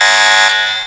alarm03.wav